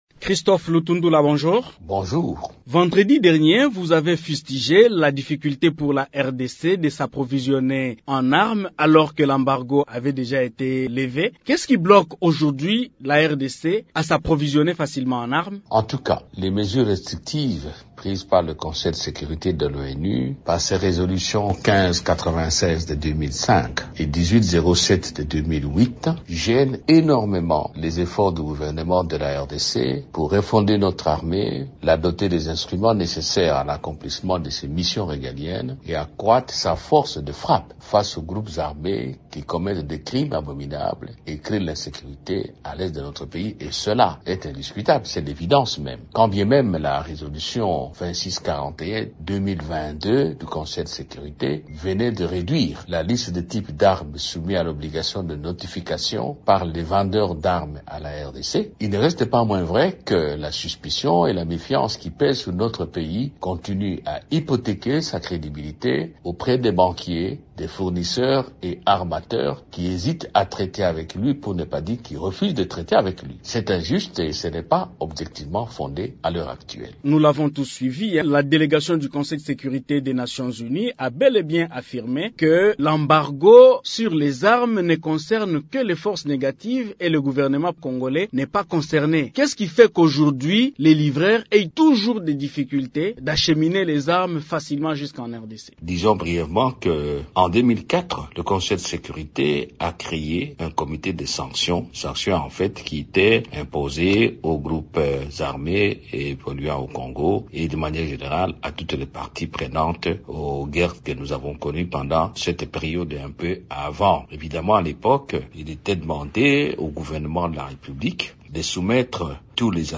Invité de Radio Okapi ce jeudi 17 novembre, il a affirmé que les conditionnalités posées aux vendeurs et transporteurs avant de livrer les armes à la RDC seront levées ; car les démarches sont déjà en cours.